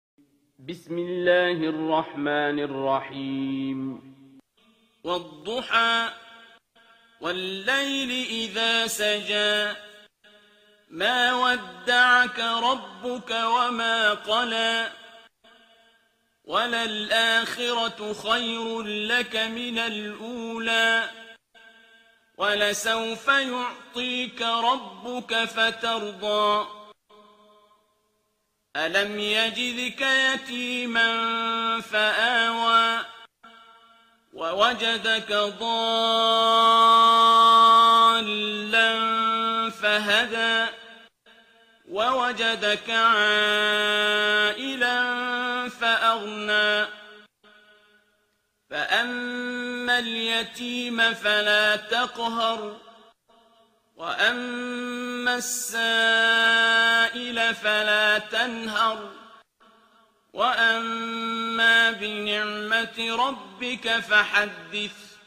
ترتیل سوره ضحی با صدای عبدالباسط عبدالصمد
093-Abdul-Basit-Surah-Ad-Dhuha.mp3